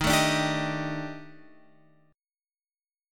D# Major 7th Flat 5th